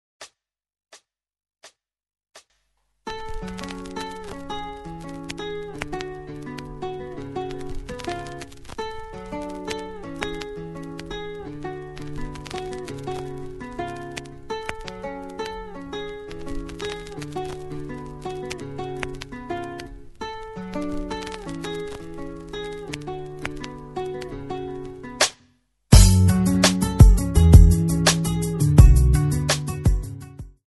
Buy With Backing Vocals.
F#m
Backing track Karaoke
Pop, Duets, 2000s